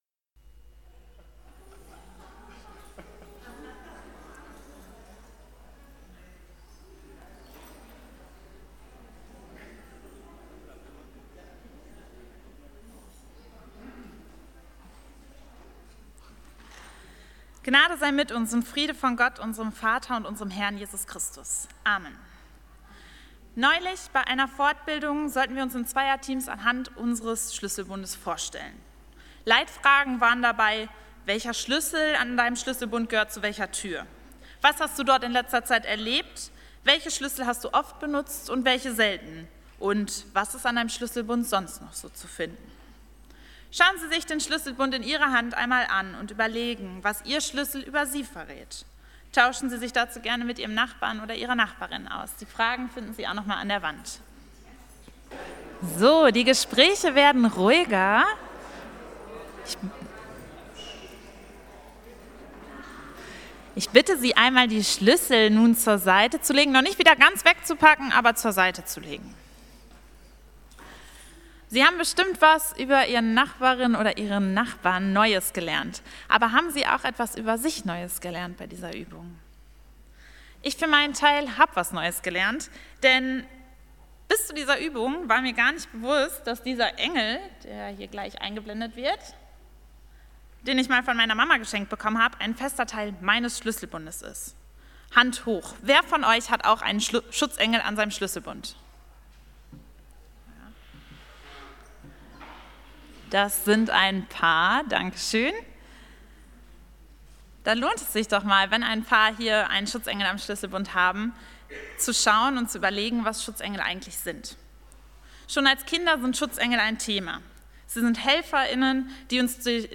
Gottesdienst
Aug 2, 2023 | Predigten | 0 Kommentare